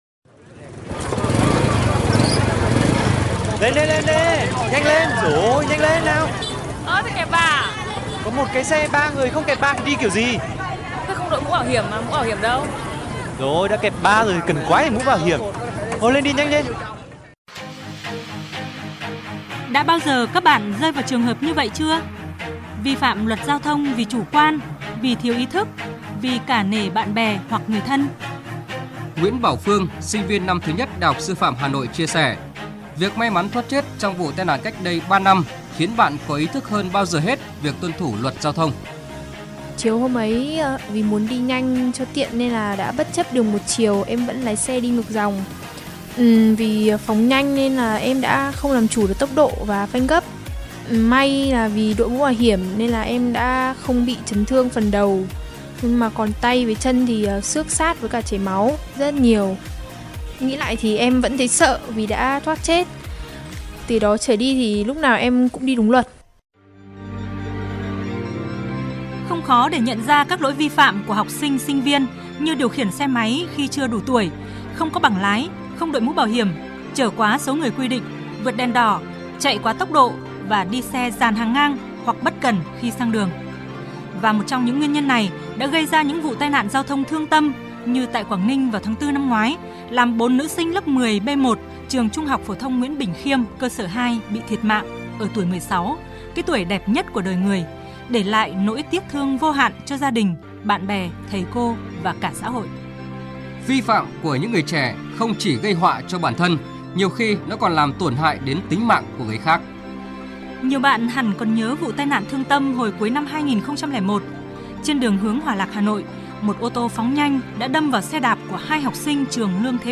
Phóng sự tuyên truyền về an toàn giao thông